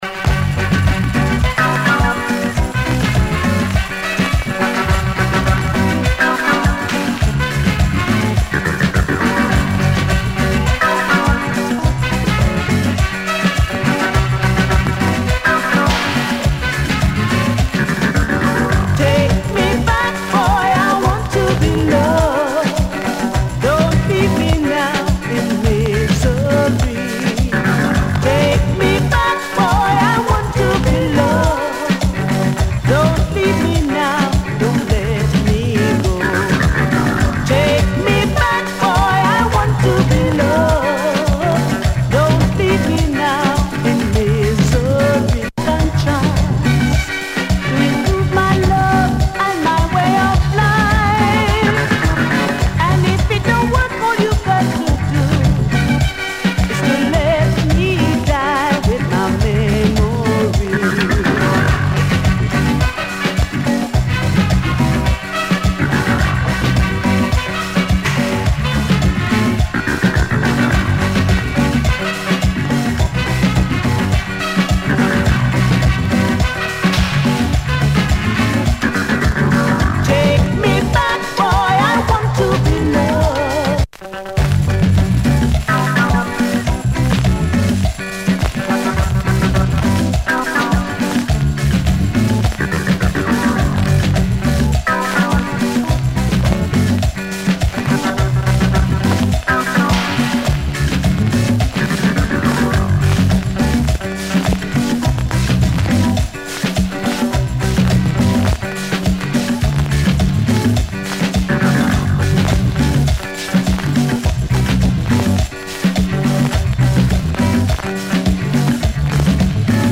German tourist disco!